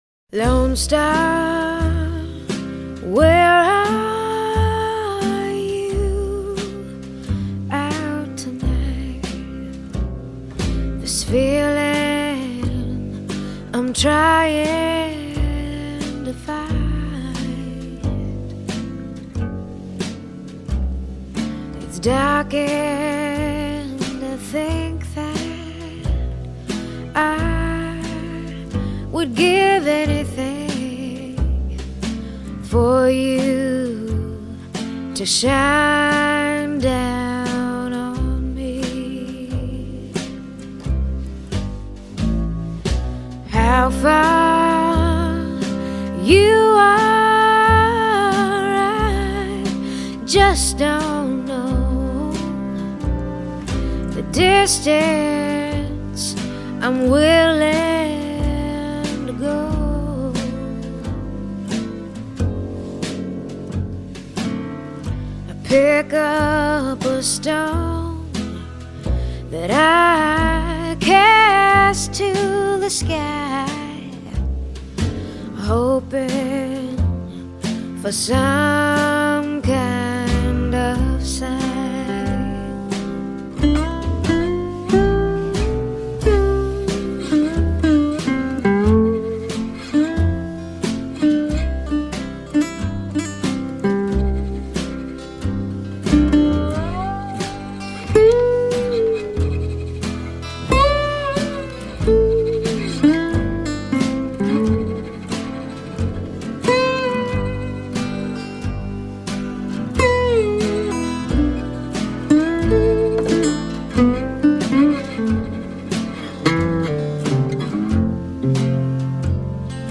音乐类型：爵士乐
轻松的音乐让人心情放松